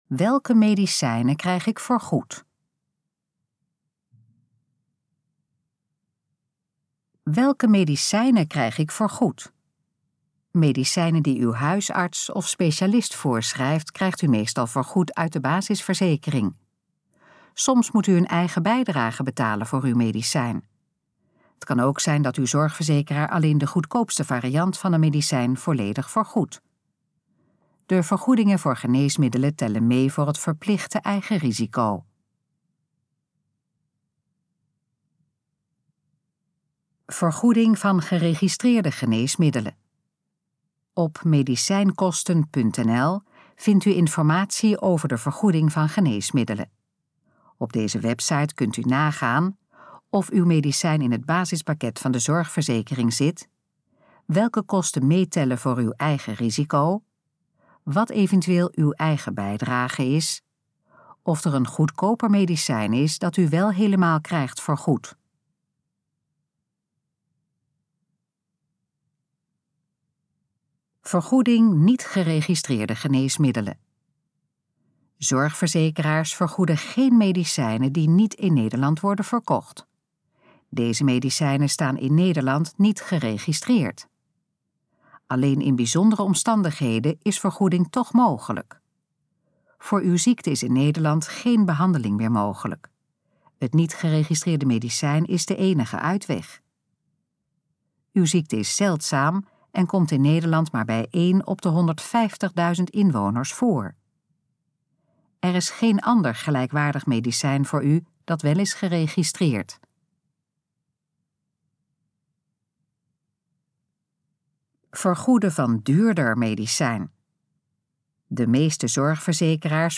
Dit geluidsfragment is de gesproken versie van de pagina: Welke medicijnen krijg ik vergoed?